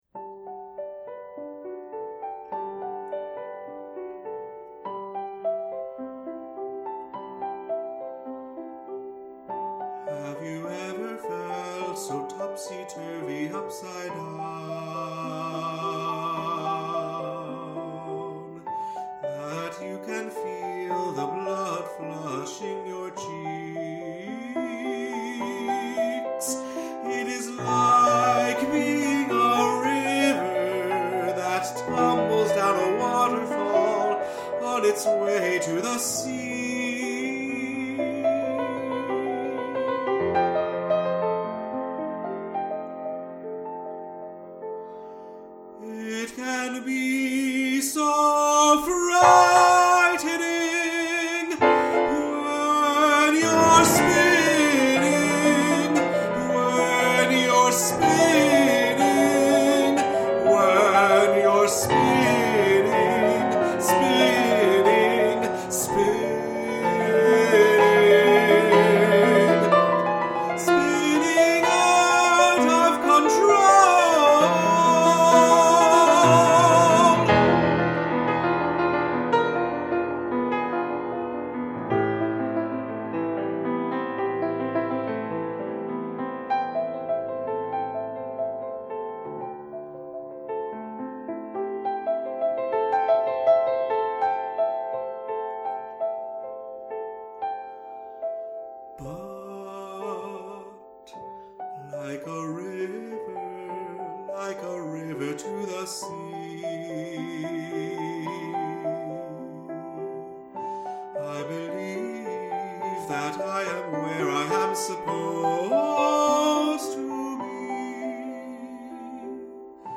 Tenor & piano